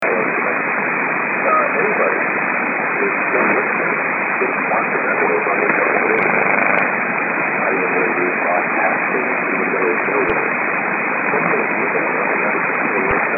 A site mainly dedicated to Mediumwave Dxing
US pirate heard in the past on SW